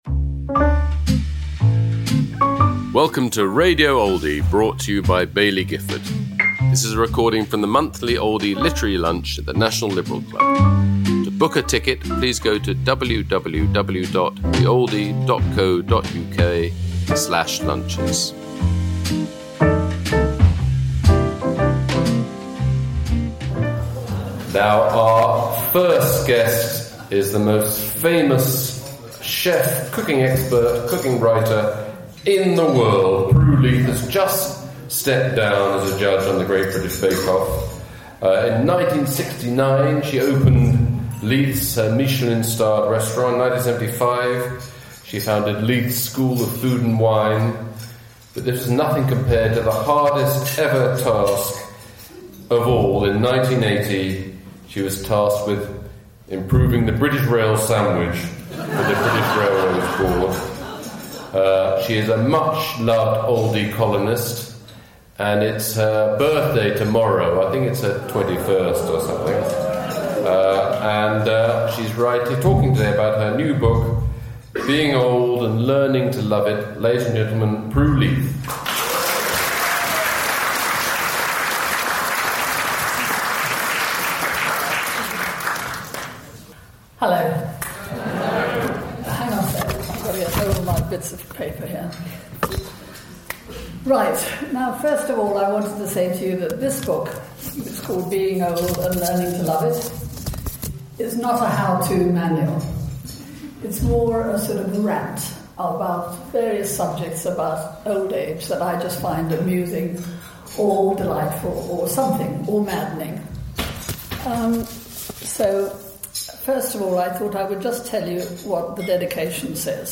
Pru Leith speaking about her new book, Being Old and Learning to Love It!, at the Oldie Literary Lunch, held at London’s National Liberal Club, on February 17th 2026.